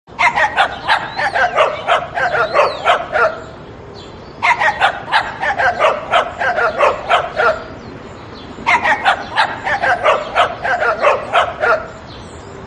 Catégorie: Animaux